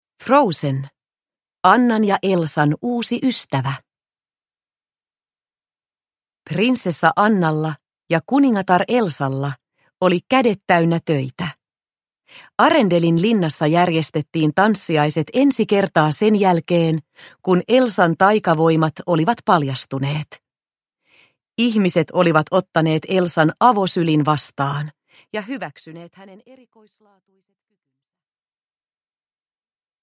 Frozen. Annan ja Elsan uusi ystävä – Ljudbok – Laddas ner